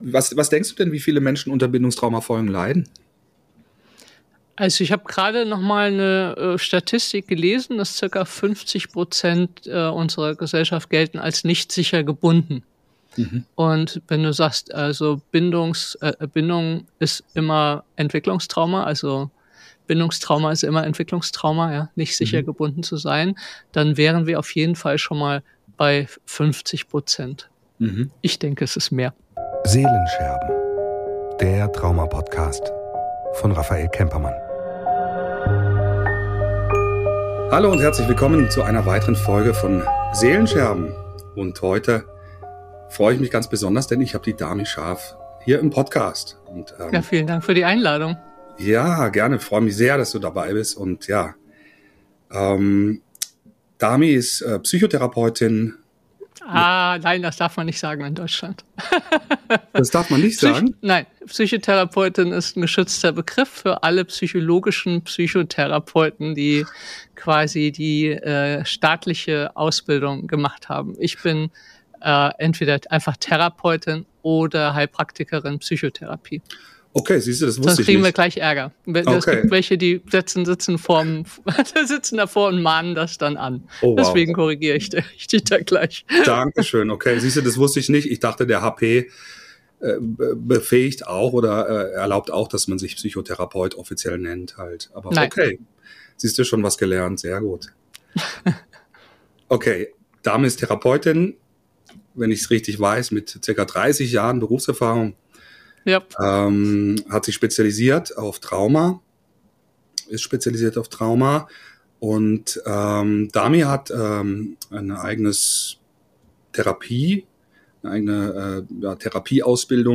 im Gespräch: Trauma – der verborgene Schmerz in uns